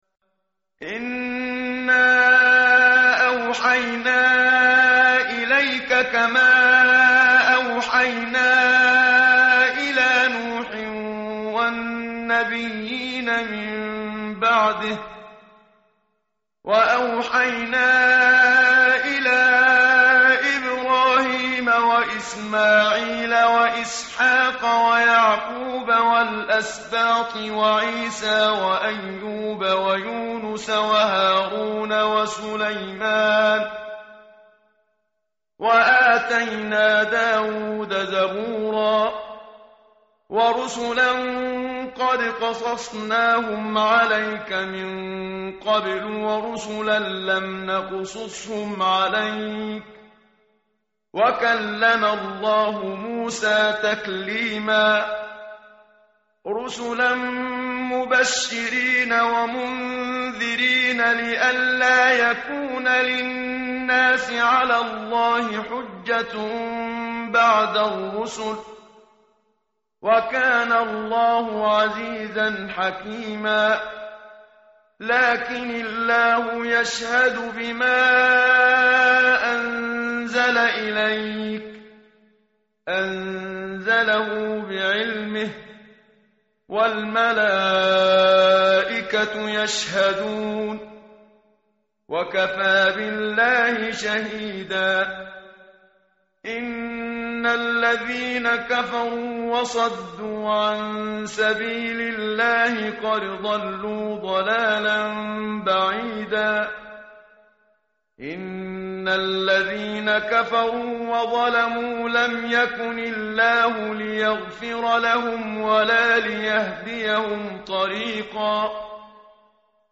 متن قرآن همراه باتلاوت قرآن و ترجمه
tartil_menshavi_page_104.mp3